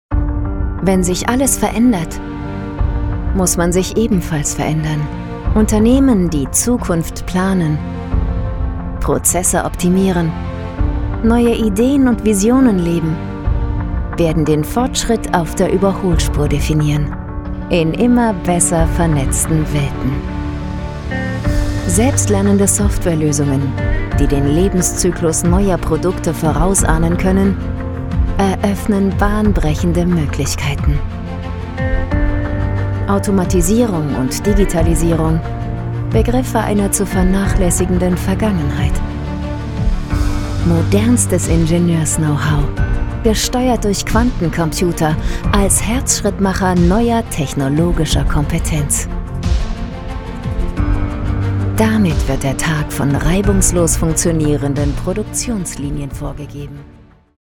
Professionelle Sprecherin mit eigenem Tonstudio, Stimmlage mittel bis hoch, Stimmalter 20-45.
Sprechprobe: Industrie (Muttersprache):
german female speaker, professional voice actor, recording studio, commecial, documentary, voiceover, e-Learnig, stationvoice